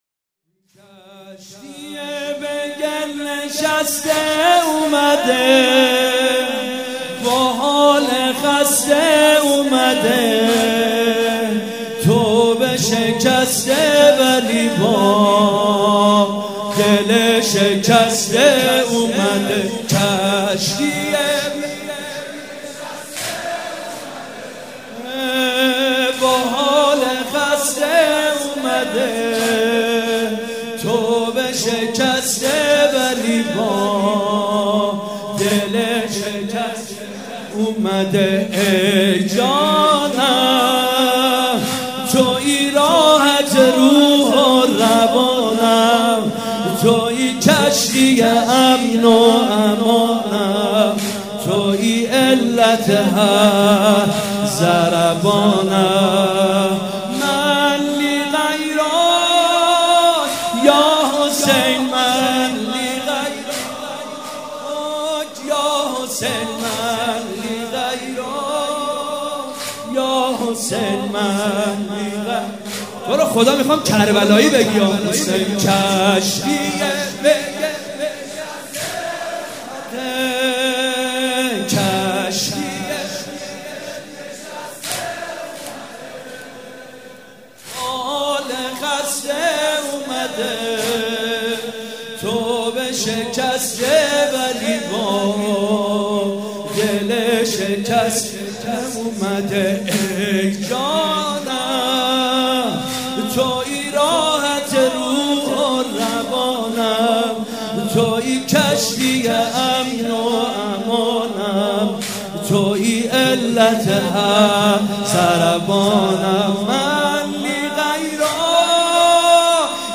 شب دوم محرم الحرام‌ دوشنبه ۱۲ مهرماه ۱۳۹۵ هيئت ريحانة الحسين(س)
واحد مداح
مراسم عزاداری شب دوم